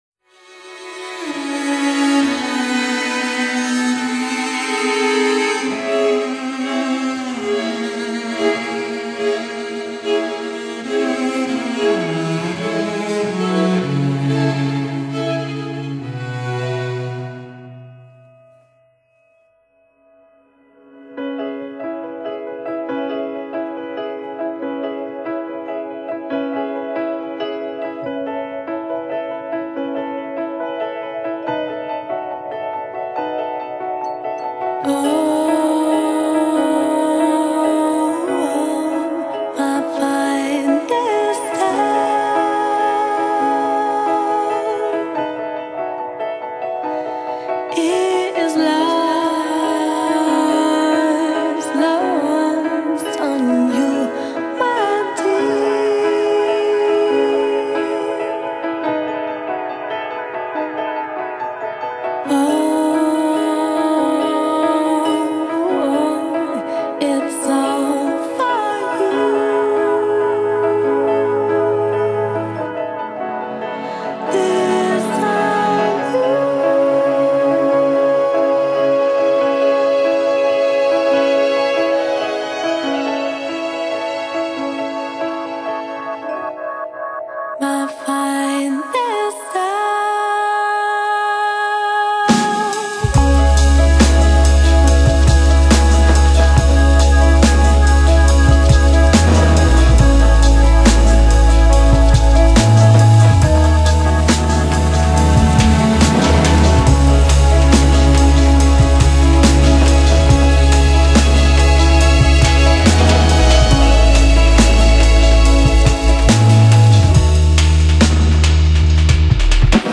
Genre: Lounge, Downtempo, Lo-Fi, Acid Jazz, Deep House